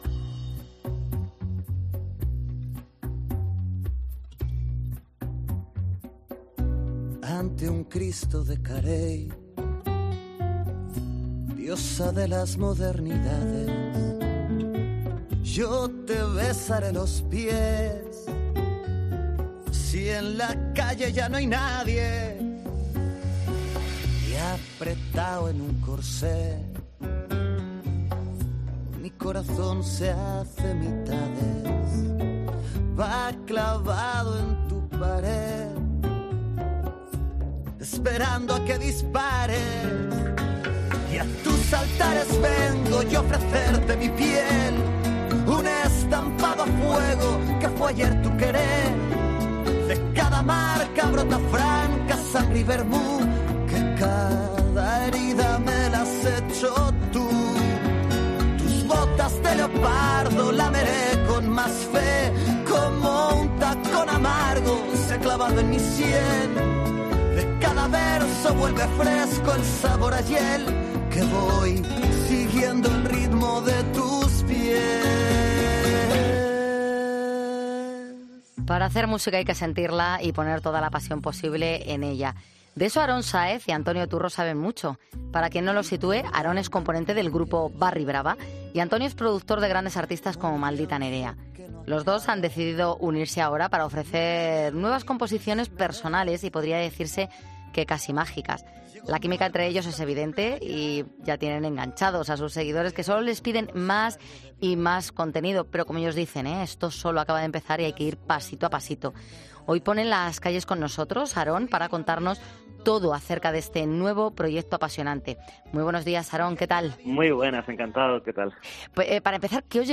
El componente del grupo de Varry Brava ha pasado por los micrófonos de 'Poniendo las Calles' para contarnos todo sobre este nuevo trabajo que va...